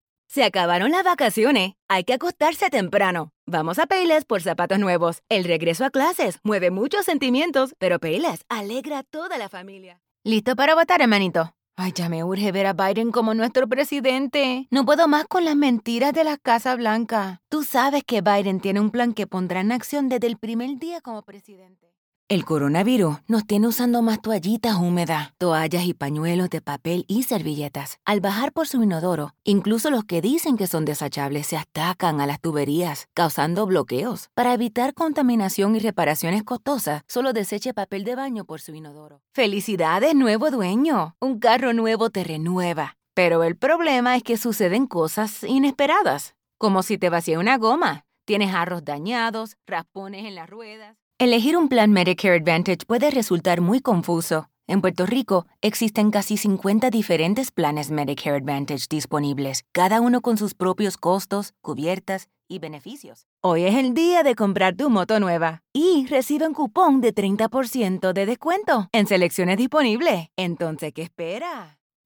Voice reels